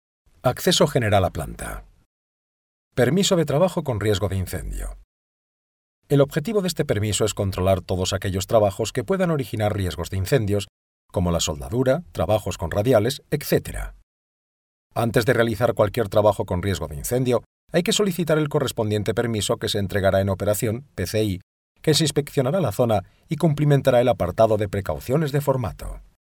voz española versatil , calida ,experiencia en narracion , e-learning , publicidad y doblaje
kastilisch
Sprechprobe: Industrie (Muttersprache):